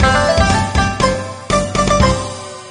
SFX卡牌游戏胜利音效下载
SFX音效